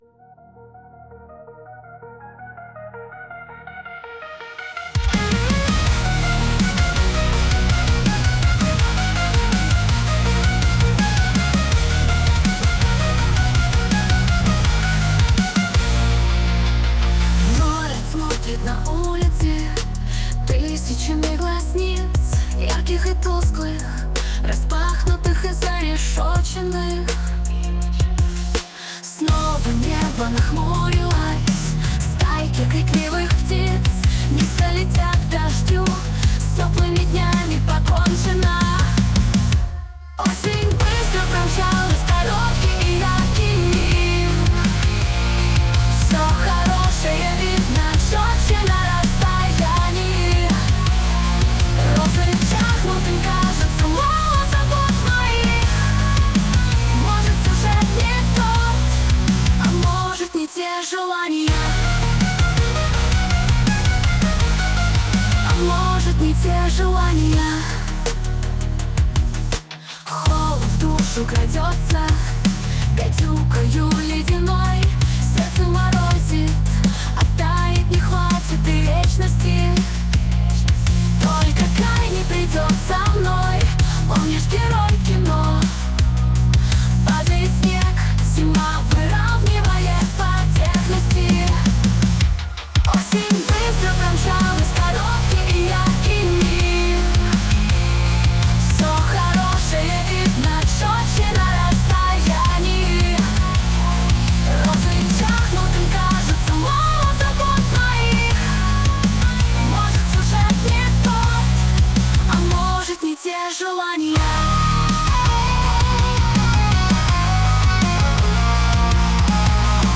Исполняет ИИ